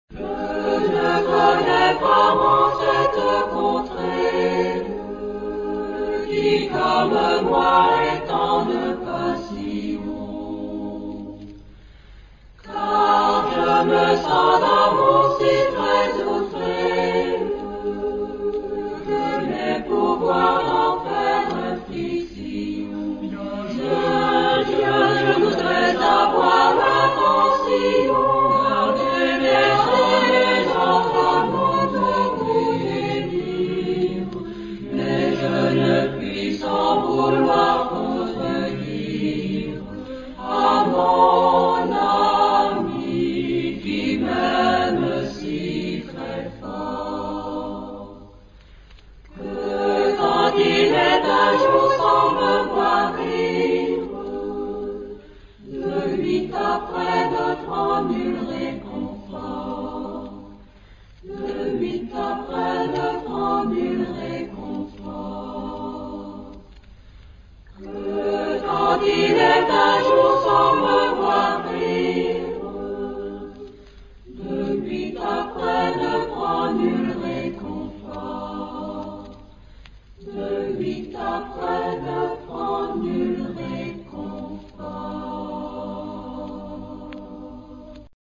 Genre-Style-Forme : Profane ; Renaissance ; Chanson
Type de choeur : SATB  (4 voix mixtes )
Tonalité : sol mode de sol